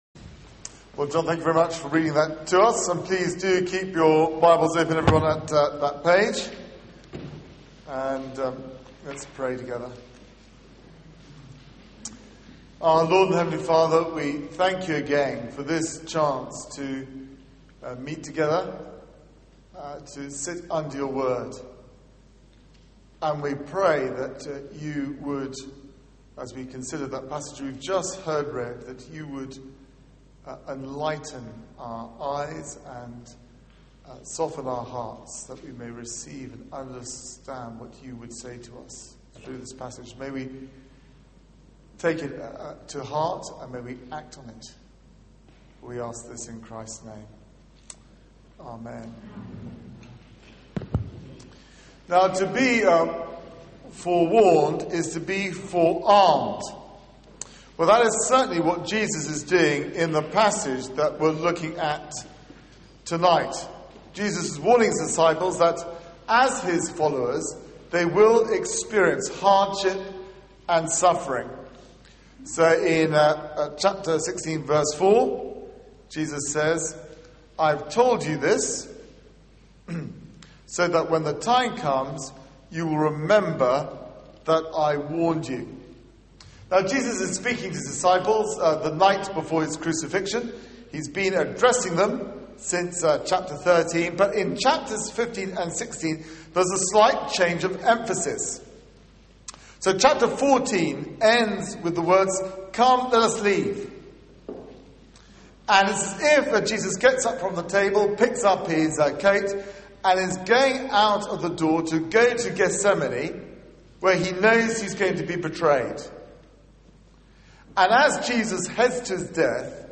Media for 6:30pm Service on Sun 06th Nov 2011
Series: Famous last words Theme: 'You do not belong to the world' Sermon